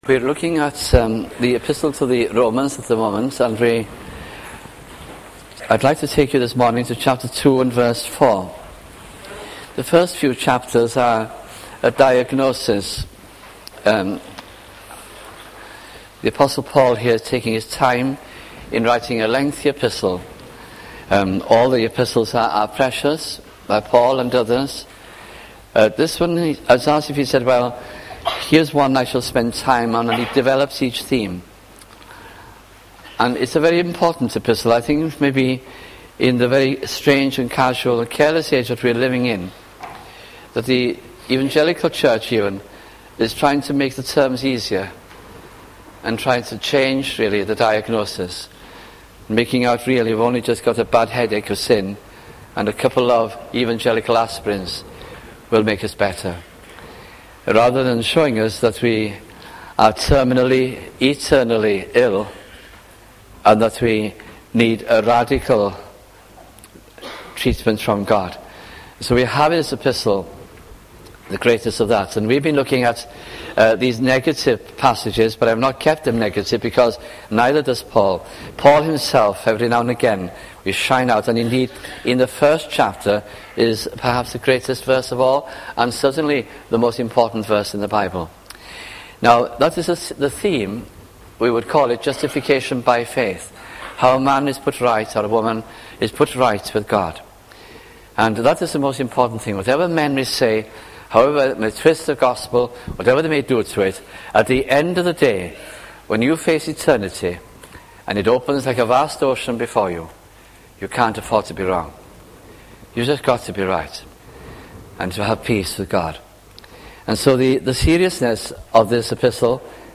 » Romans 1996-98 » sunday morning messages